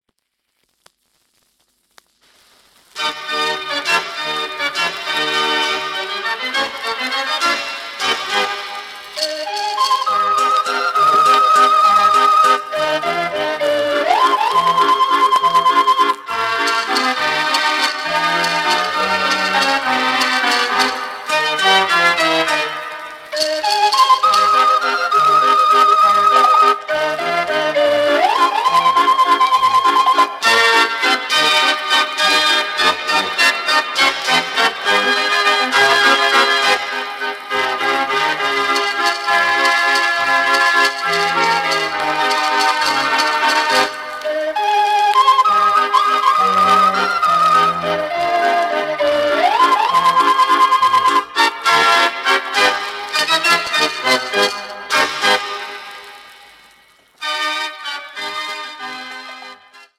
Formaat 78 toerenplaat, 10 inch